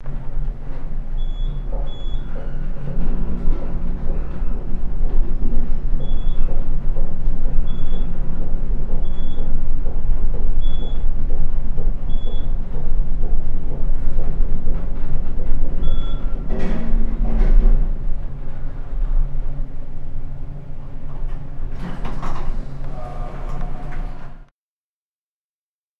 elevator
bell ding elevator field-recording machine rattle rumble sound effect free sound royalty free Sound Effects